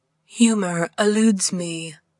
Silly Male Laugh Hehehe lol
描述：A man chuckling.
标签： voice performance actor over humor funny
声道立体声